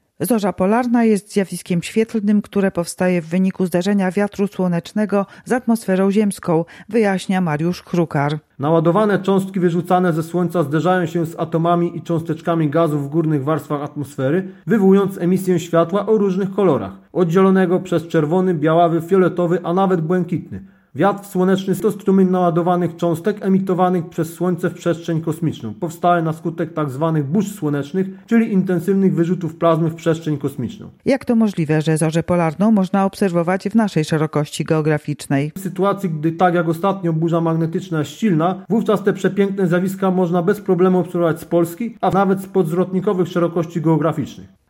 lok-zorza-polarna-ROZMOWA.mp3